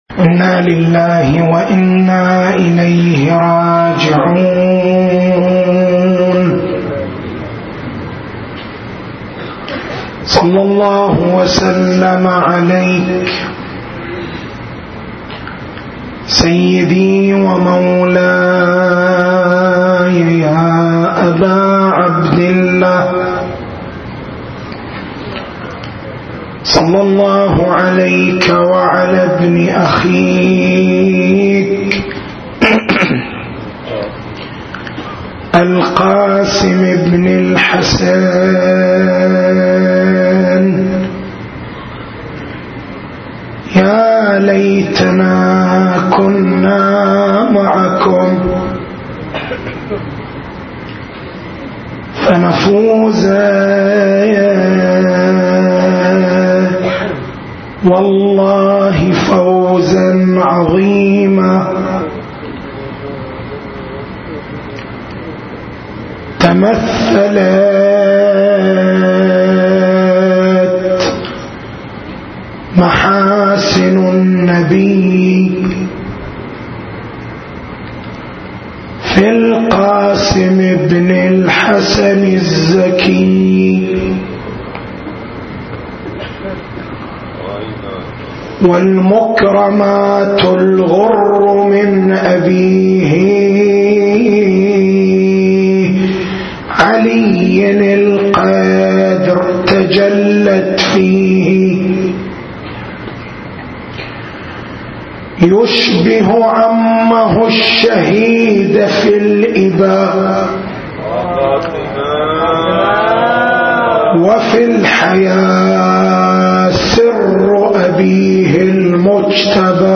تاريخ المحاضرة: 08/01/1434